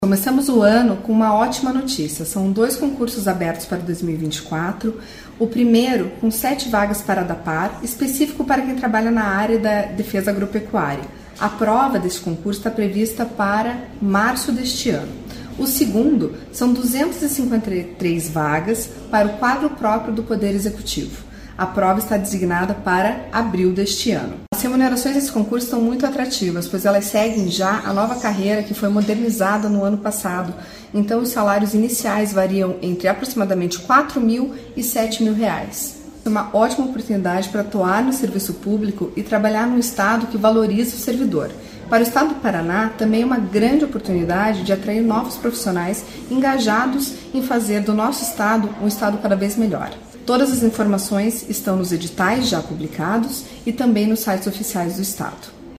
Sonora da secretária da Administração e Previdência em exercício, Luiza Corteletti, sobre o anuncio de concursos públicos com 260 vagas para várias funções em 2024